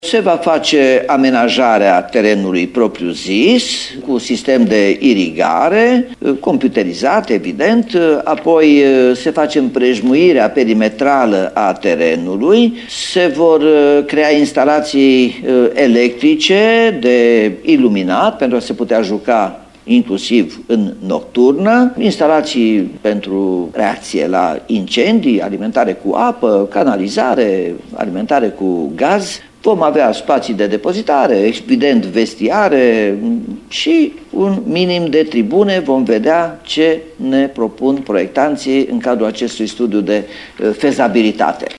Stadionul „Dacia” a fost folosit ultima oară în competițiile oficiale în sezonul 2008-2009, de echipa secundă a CFR-ului, pe atunci în Liga a IV-a. Arena ar urma să fie refăcută în totalitate și va beneficia de instalație de nocturnă, spune Robu: